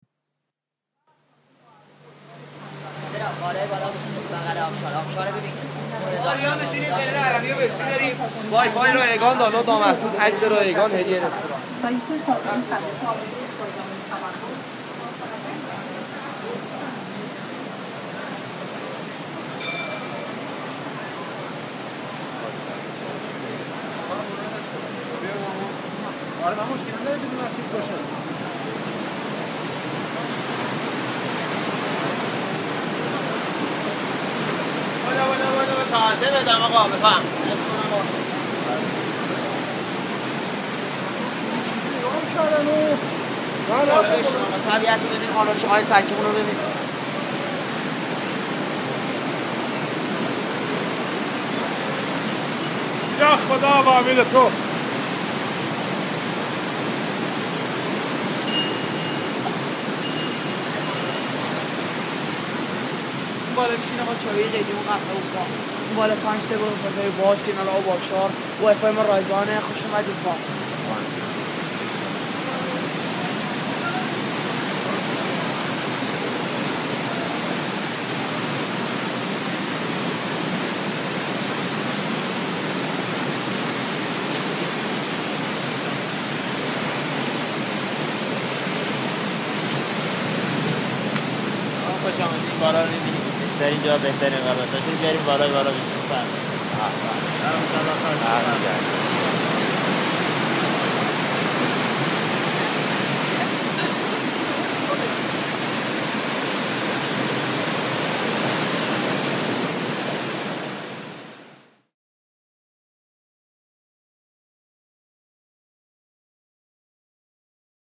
This recording was taken with an iPhone-mounted mic in a late spring afternoon, just before the supper time as I was walking along a beautiful narrow pedestrian-only passage along the mountain and the river carving through the mountains in norther Tehran.
Popular amongst the citizens to escape from the hustle and bustle of Tehran, hosts of these restaurants standing in front of them deliver a short 5 second pitch on why the passers-by should go to their restaurant, repeating it for each group walking by.
The mere act of sounding and talking to attract customers, a lost art in the west, with different intonations and pitches, like a polyphony of promises.
All of this in a beautiful setting washed by the massive sound of the river making it so tactile yet so unattainable.